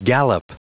Prononciation du mot gallop en anglais (fichier audio)
Prononciation du mot : gallop